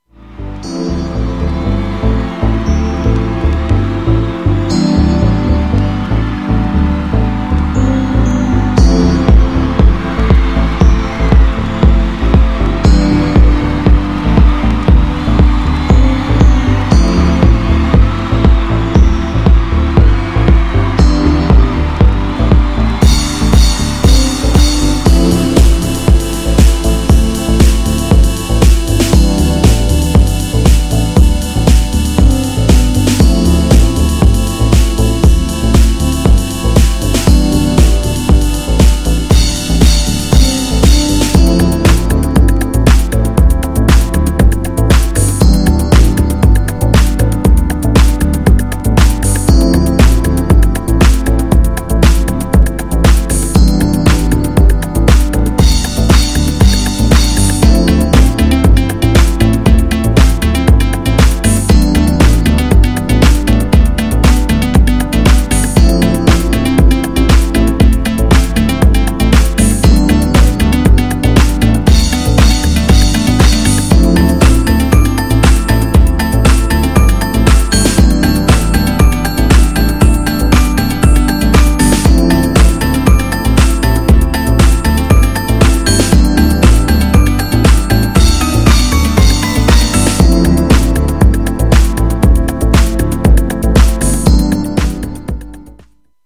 ドイツのテクノPOP系のアーティストの1stにして、アンダーグラウンドなバレアリック・クラシック!!
INSTはUS HOUSEのようなDEEPさを持っていてオススメ!!
GENRE Dance Classic
BPM 116〜120BPM
EURO_DISCO
# アンビエント # ダーク # テクノ # バレアリック # 空間的